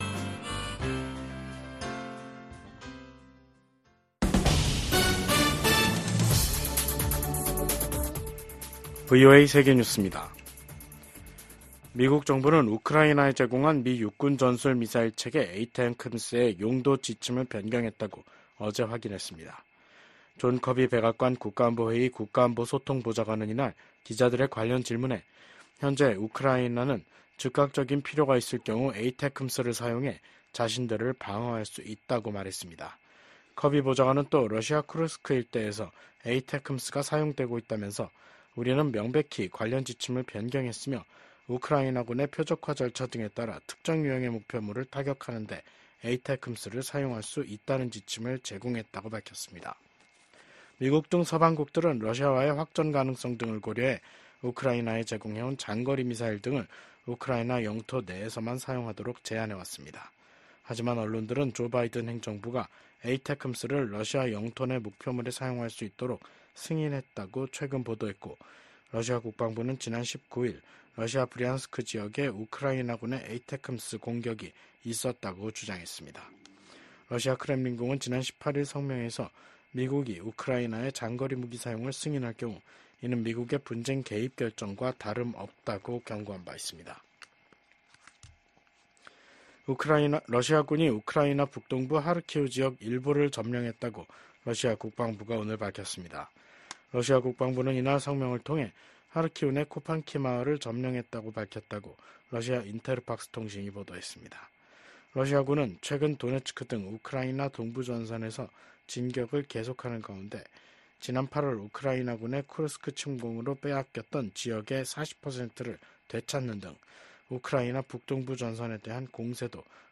VOA 한국어 간판 뉴스 프로그램 '뉴스 투데이', 2024년 11월 26일 3부 방송입니다. 미국은 북한군이 현재 우크라이나로 진격하지는 않았다고 밝혔습니다. 러시아가 북한에 파병 대가로 이중용도 기술과 장비를 판매하고 있다고 미 국무부가 밝혔습니다. 북러 군사 밀착이 북한 군의 러시아 파병으로까지 이어지고 있는 가운데 북중 관계는 여전히 냉랭한 기운이 유지되고 있습니다.